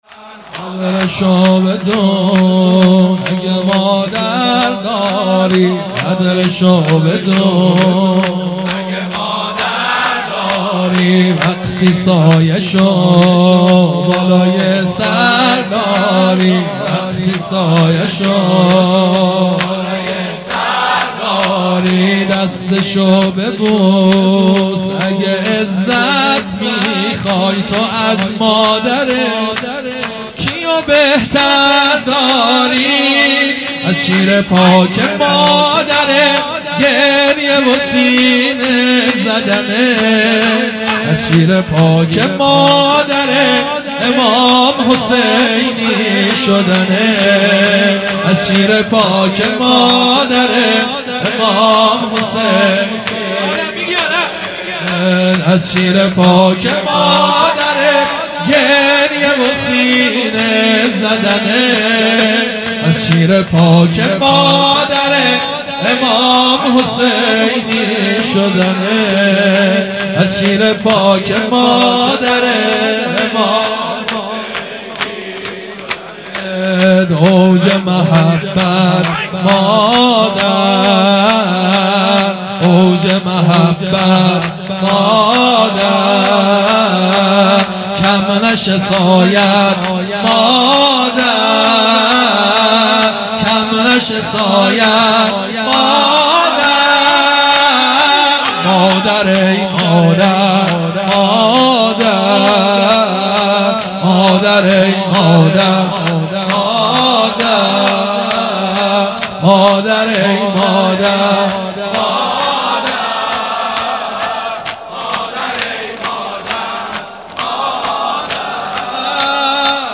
هیئت مکتب الزهرا(س)دارالعباده یزد
0 0 شور | قدرشو بدون اگه مادر داری مداح